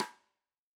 Index of /musicradar/Snares/Tama Wood